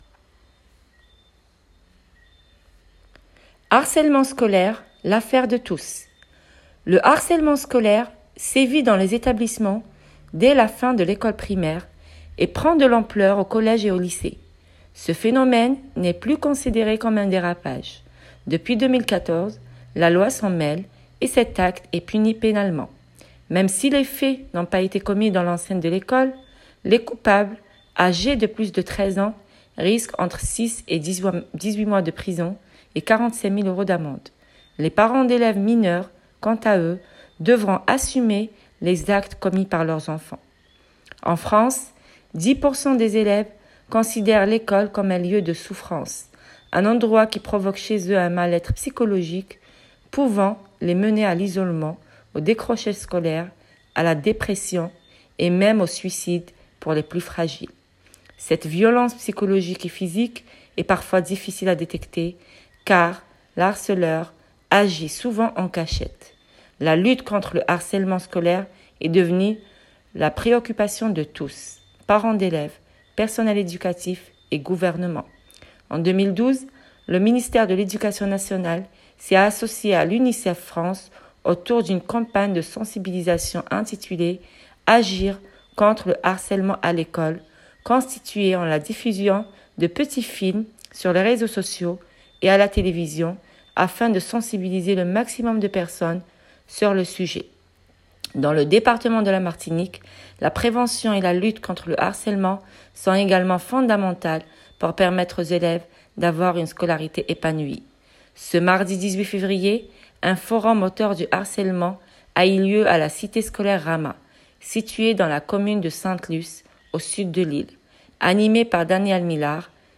Interventions